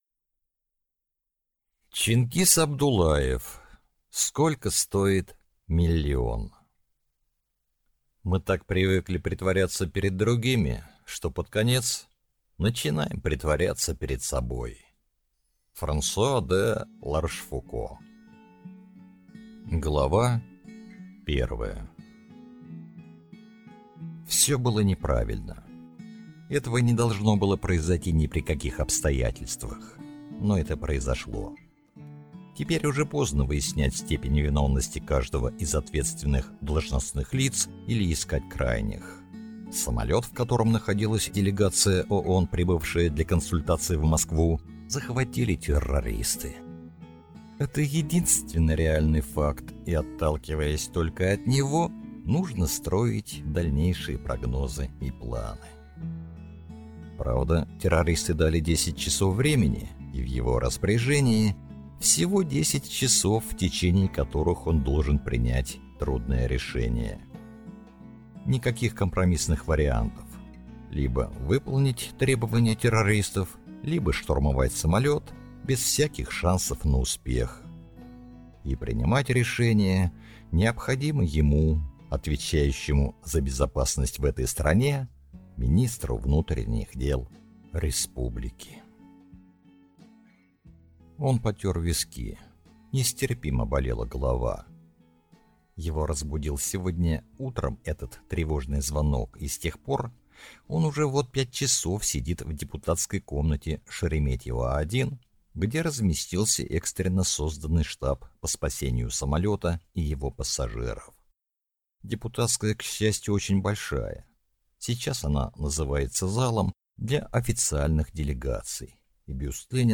Аудиокнига Сколько стоит миллион | Библиотека аудиокниг